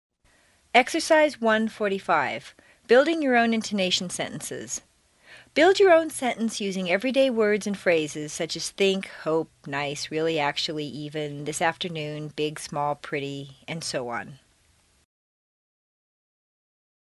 美语口音训练第二册14 听力文件下载—在线英语听力室